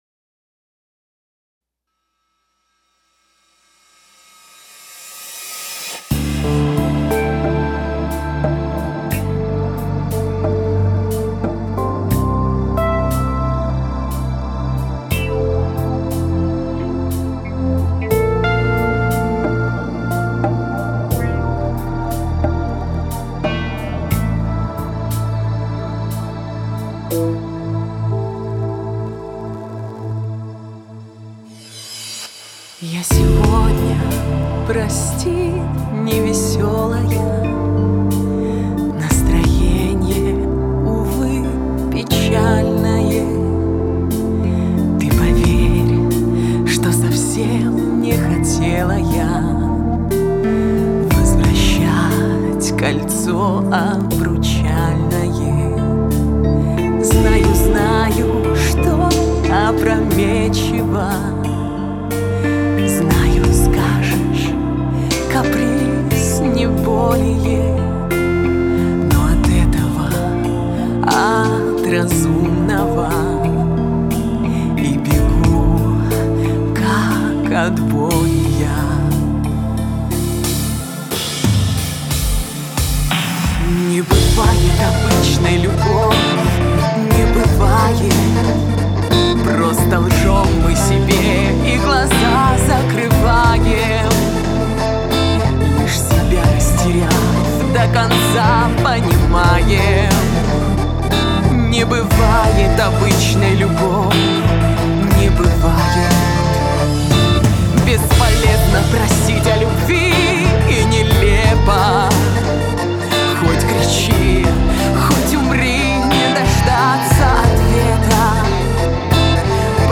Как минимум с голосом намного все стало лучше. Возможно только в последней части голос хочется чуть громче фанеры.
Теряется он внутри этой фанерной "массы".